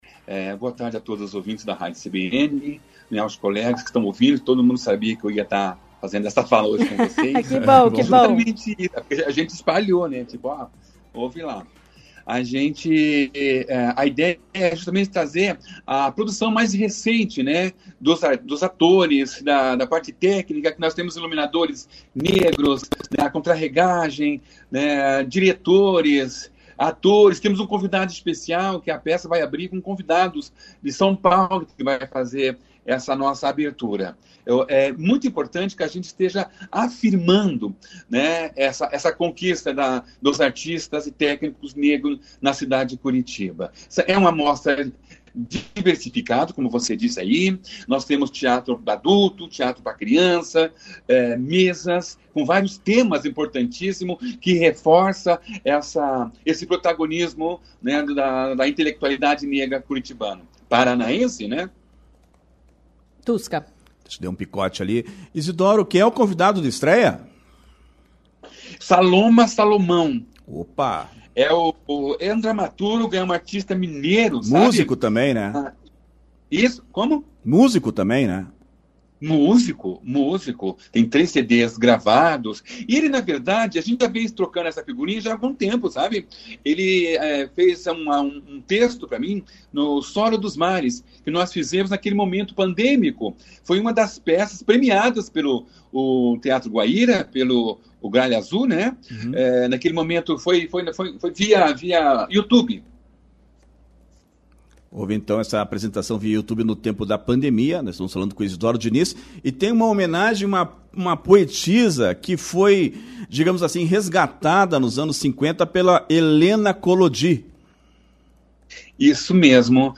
Em entrevista à CBN Curitiba